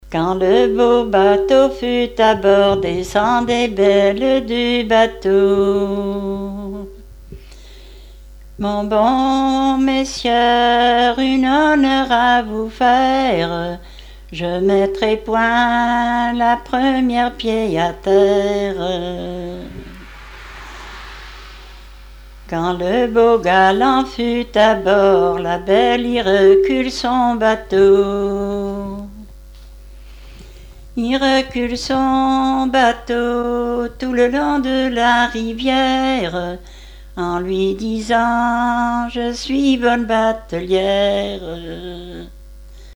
Genre strophique
Répertoire de chansons populaires et traditionnelles